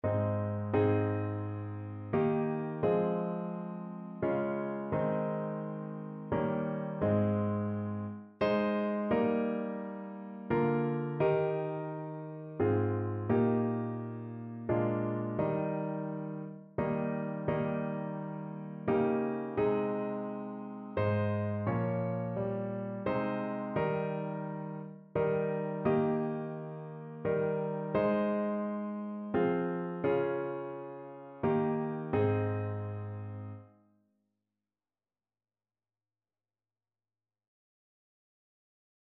Gebet
Notensatz 1 (4 Stimmen gemischt)
• gemischter Chor mit Akk.